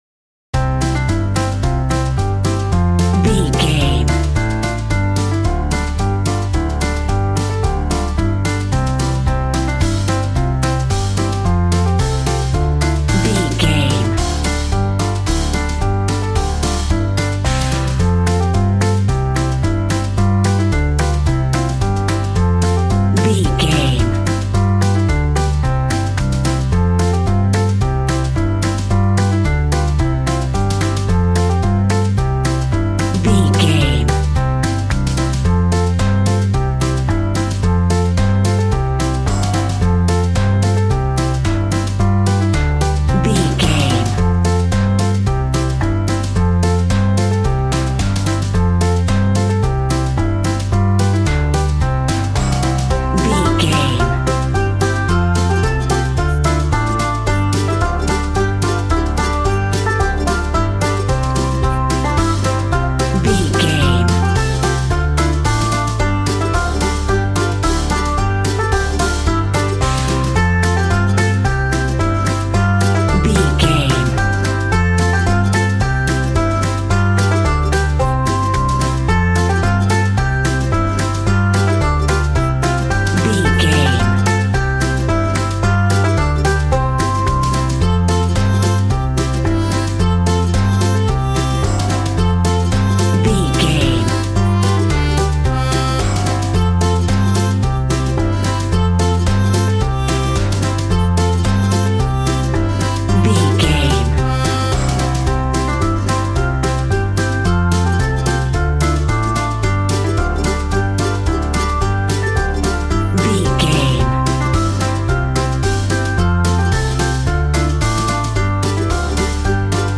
Upbeat, uptempo and exciting!
Ionian/Major
cheerful/happy
bouncy
electric piano
electric guitar
drum machine